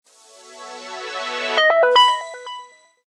PowerOn.wav